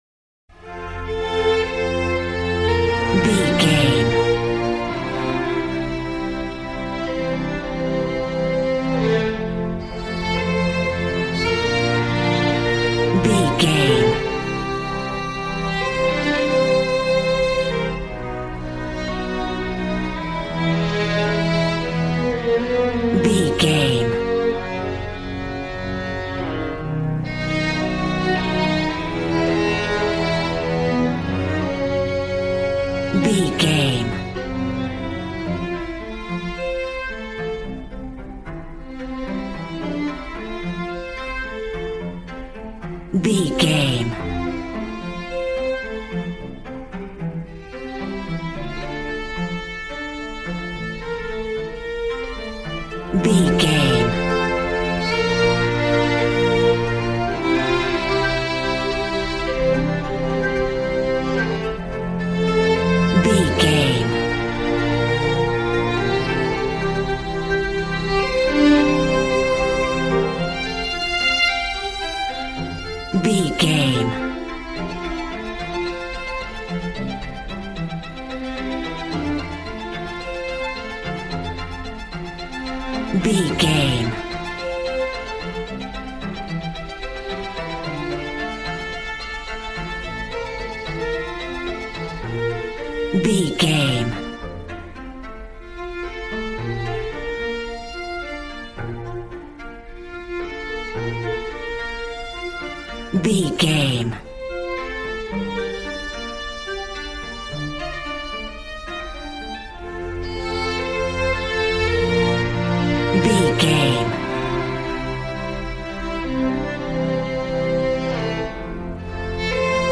Beautiful stunning solo string arrangements.
Regal and romantic, a classy piece of classical music.
Ionian/Major
regal
brass